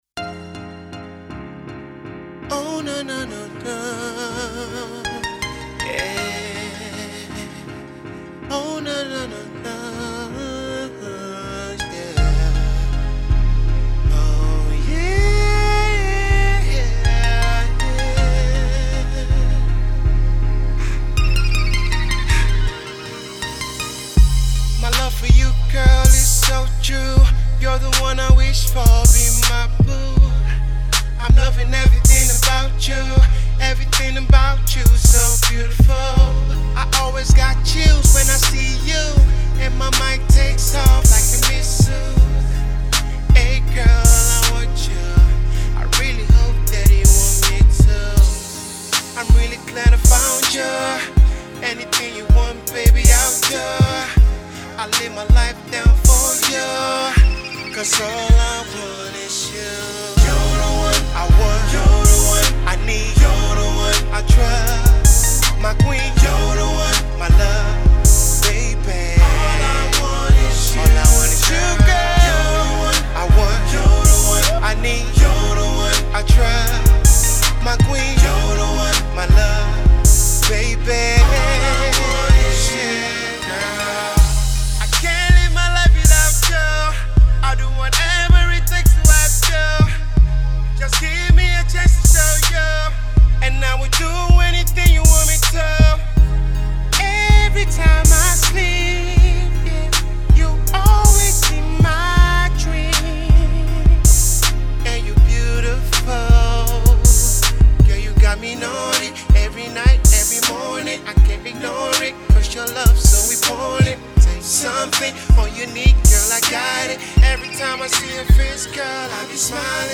Rhythm And Blues
tastefully performed Love Song
His Music is raw, real and soulfull.